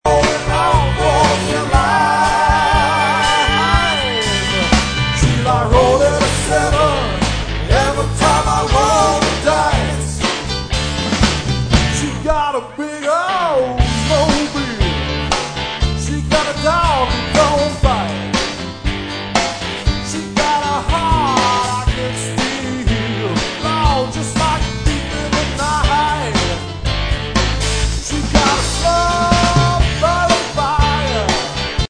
dance groove